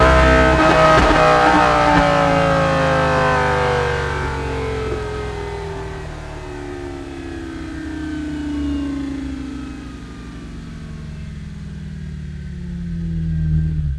rr3-assets/files/.depot/audio/Vehicles/v10_01/v10_01_Decel.wav
v10_01_Decel.wav